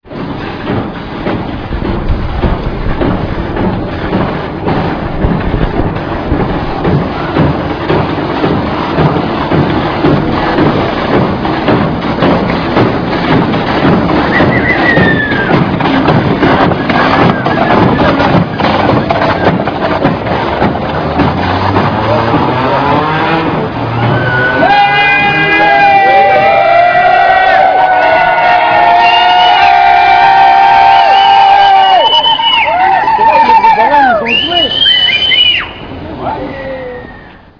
les manifestants en délire à l'arrivée devant le Teepy